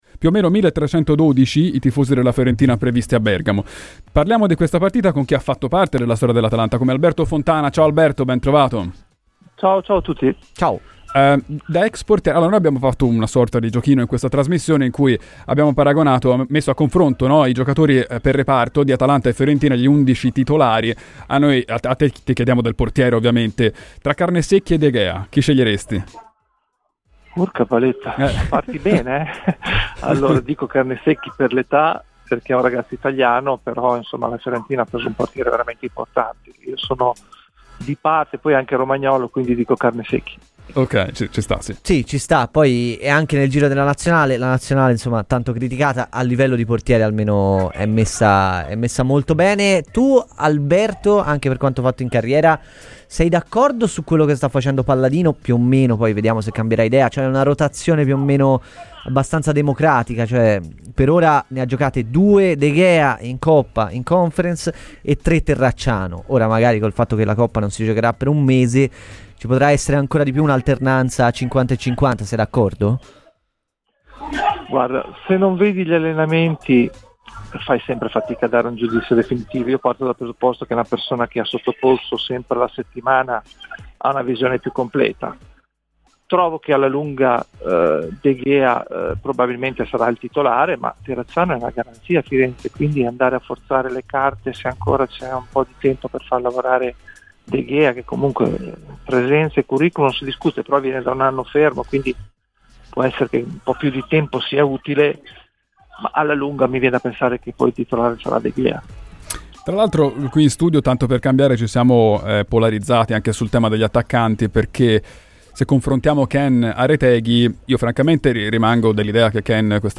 ai microfoni di Radio FirenzeViola